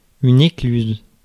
Ääntäminen
Synonyymit barrage Ääntäminen France: IPA: [e.klyz] Haettu sana löytyi näillä lähdekielillä: ranska Käännös Substantiivit 1. шлюз {m} (šljuz) Suku: f .